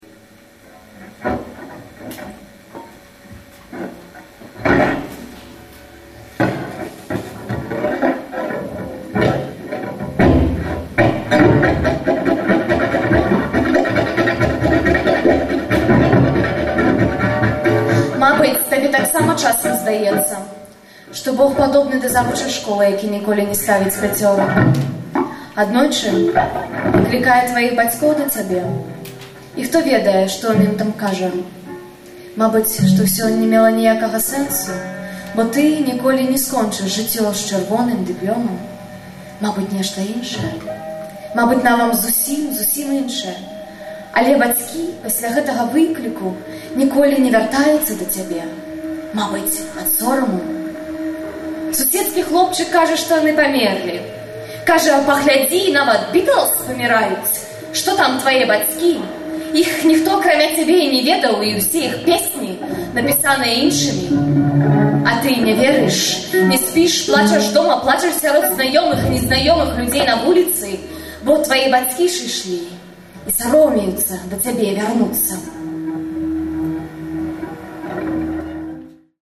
Вальжына Морт чытае свае вершы
(гітара + harmonizer + delay)
Гэта фрагмэнты выступу на фэсце “няіснае беларускае мастацтва” (20.05.06, Кемніц, Нямеччына).